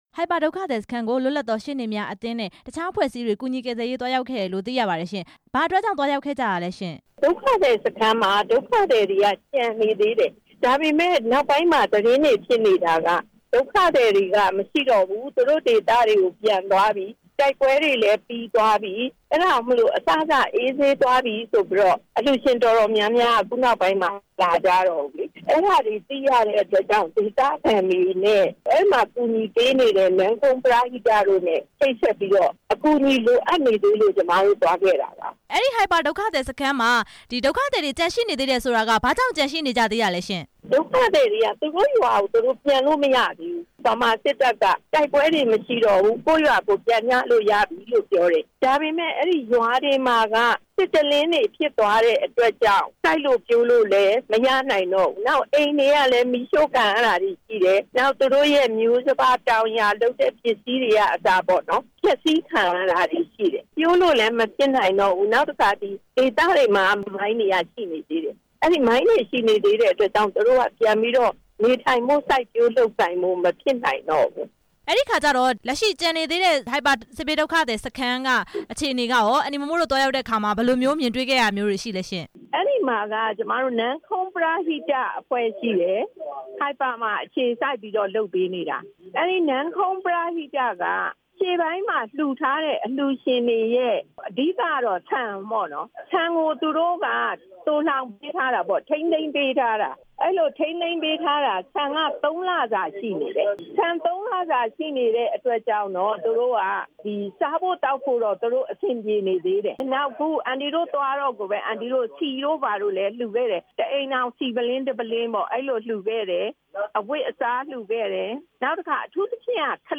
ဟိုက်ပါ စစ်ဘေးဒုက္ခသည်တွေကို စစ်တမ်းကောက်ယူမှု မေးမြန်းချက်